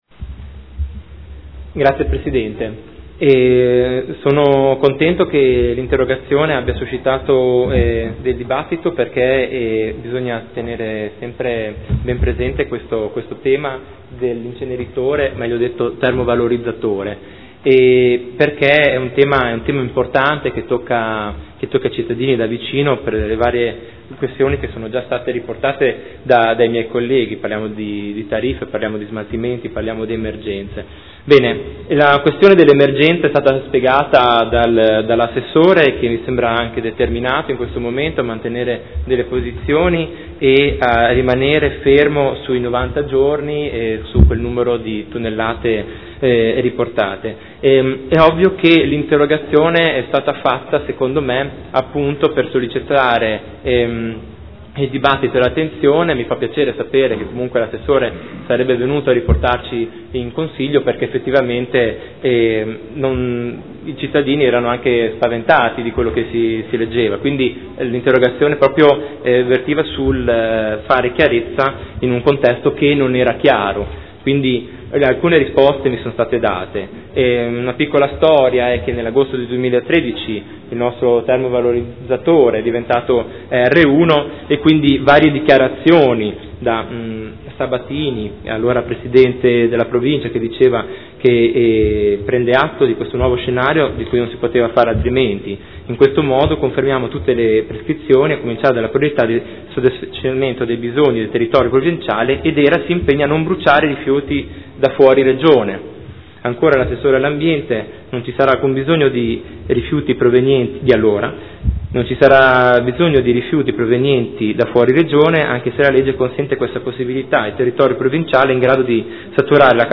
Seduta del 23/07/2015 Replica a risposta Assessore.